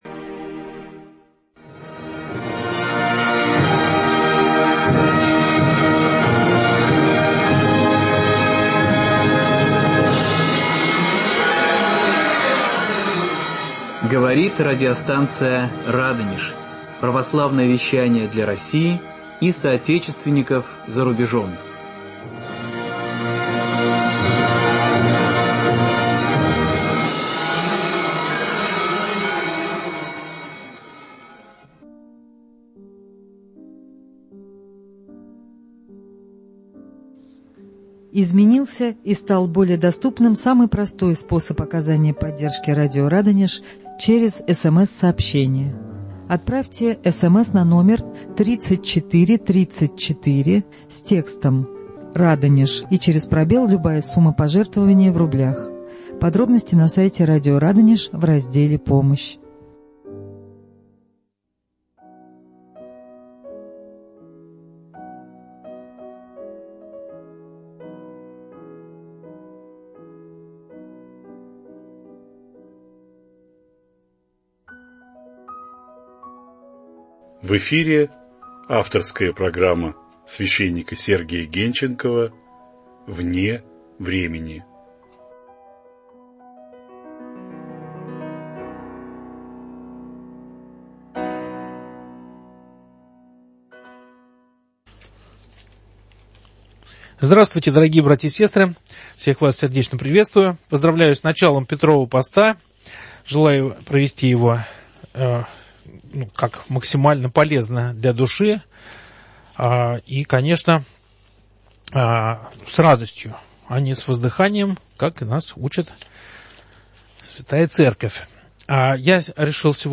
В прямом эфире радиостанции "Радонеж" на вопросы слушателей отвечает